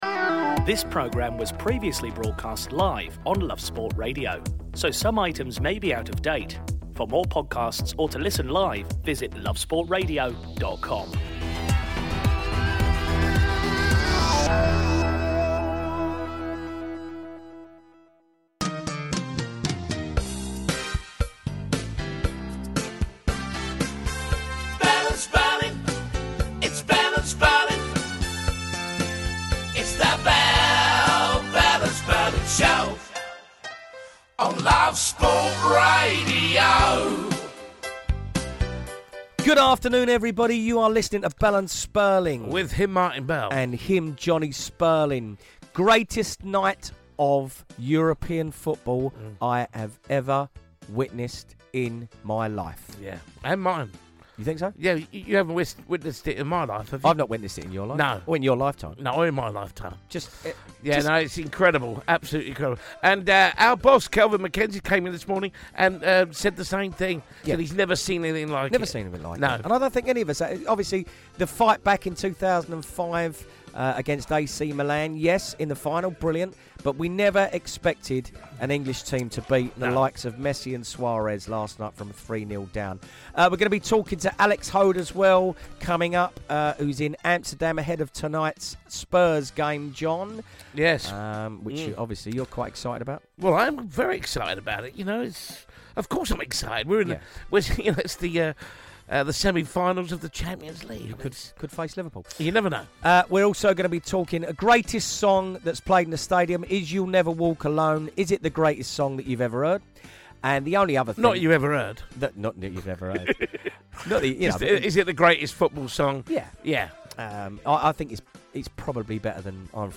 Liverpool are through to the Champions League final after the mother of all comebacks! The boys talk to fans, journalists and anyone else who wants to talk about it!